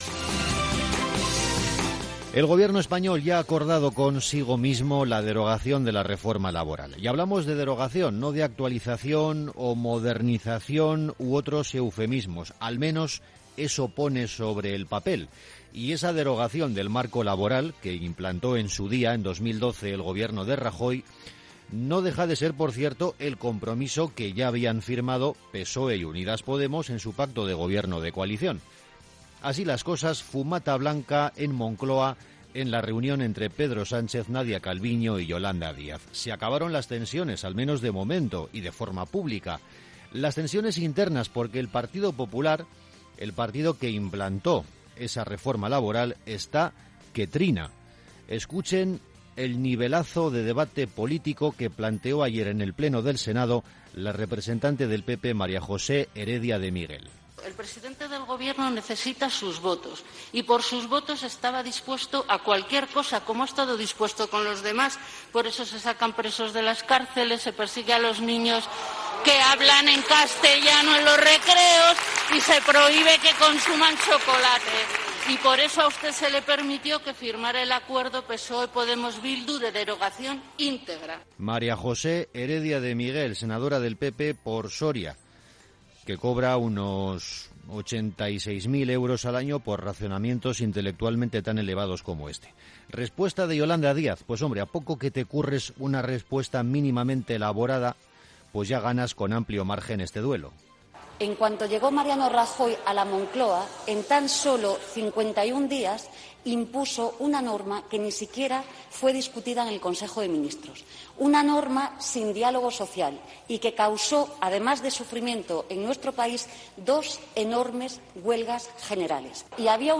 El editorial